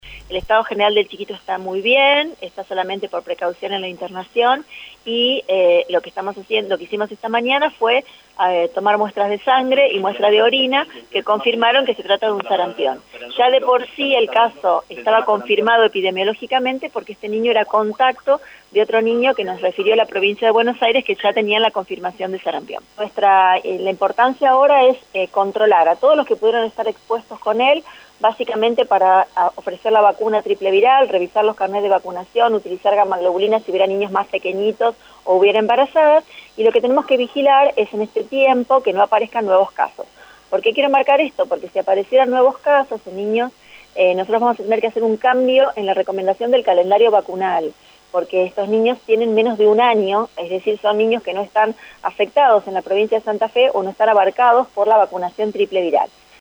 La ministra de Salud de la provincia de Santa Fe, Andrea Uboldi, brindó una conferencia de prensa para dar más detalles sobre el caso.